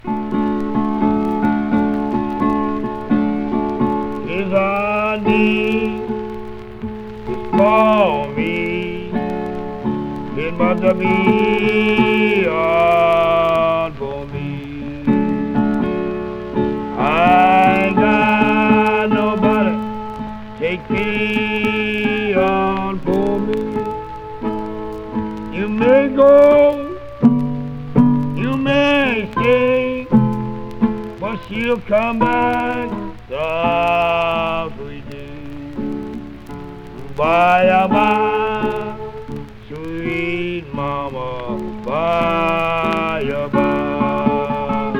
Blues　USA　12inchレコード　33rpm　Mono